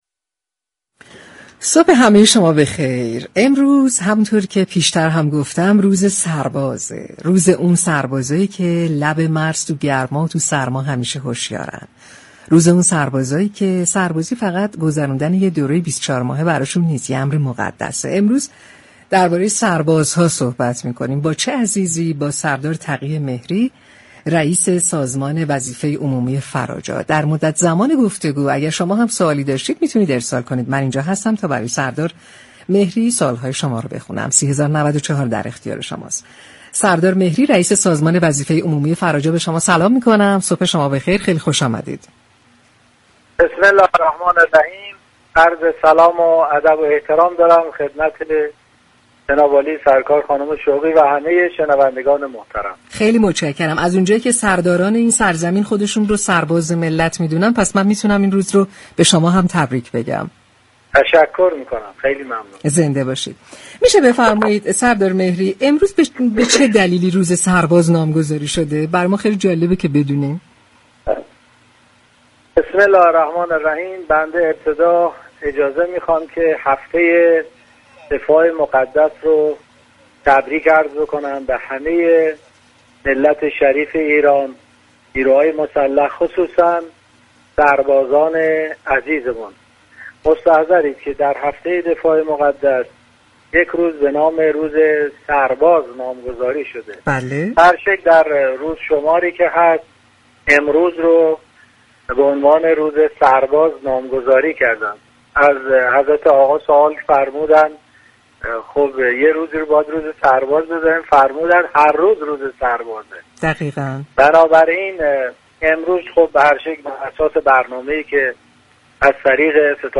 برنامه شهر آفتاب رادیو تهران روز دوشنبه 4 مهرماه به مناسبت روز سرباز با سردار تقی مهری رییس سازمان وظیفه عمومی فراجا به گفت‌وگو پرداخت.